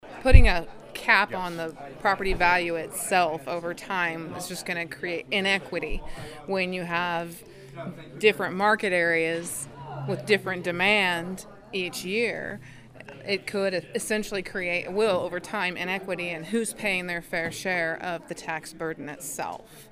Riley County Appraiser Anna Burson explains the basic issue with a valuation cap.